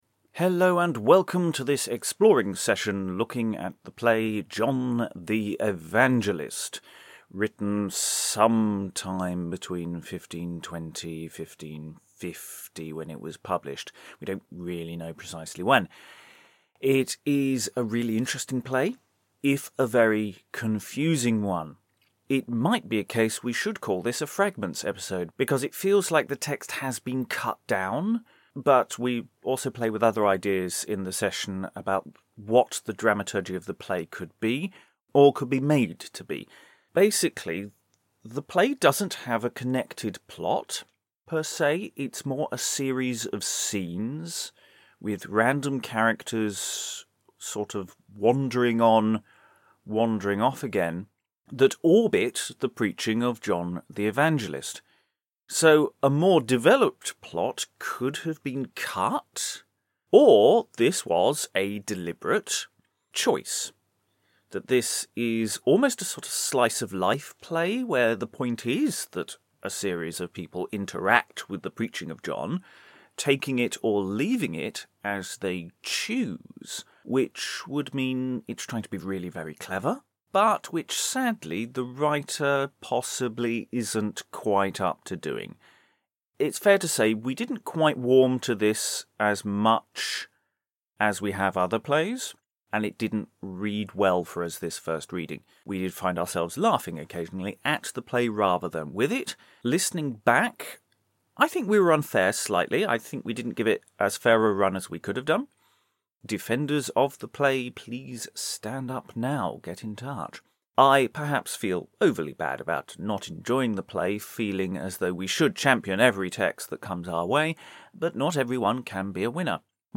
Exploring workshop - looking at the play of John the Evangelist